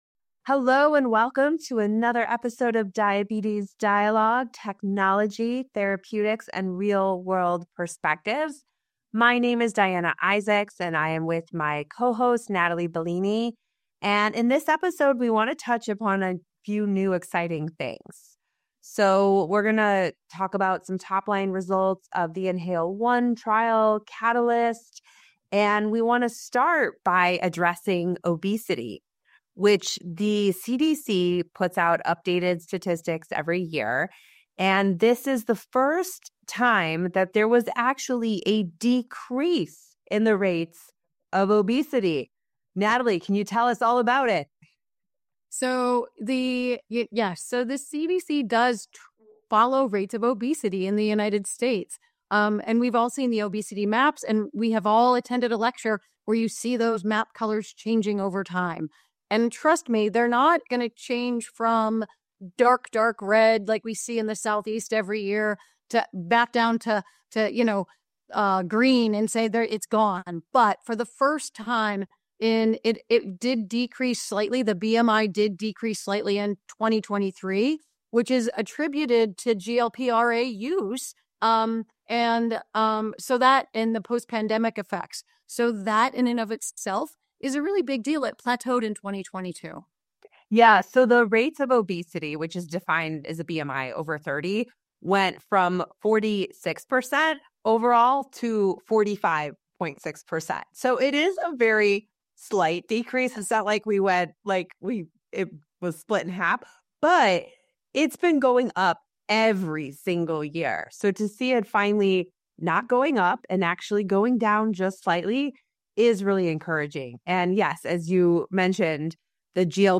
They suggest that this may be partially attributed to the impact of GLP-1 drugs, which have become increasingly popular in managing obesity and related metabolic disorders, including type 2 diabetes (T2D). With these drugs continuing to show promising results, the hosts express optimism about their potential to reduce obesity and improve long-term patient health outcomes.